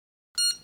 12. Терминал для оплаты с картой